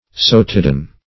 sotadean - definition of sotadean - synonyms, pronunciation, spelling from Free Dictionary Search Result for " sotadean" : The Collaborative International Dictionary of English v.0.48: Sotadean \So`ta*de"an\, a. Sotadic.